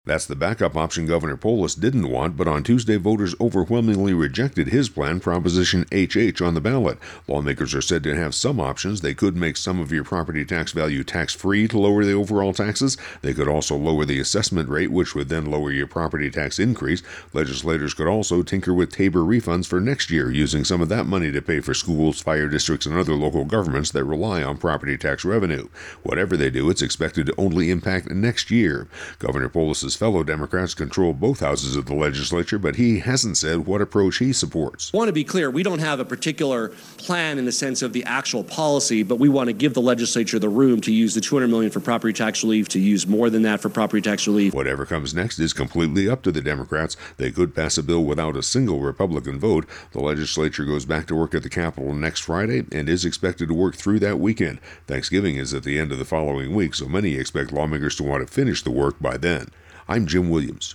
(Prop Tax Fix wrap                                      :60)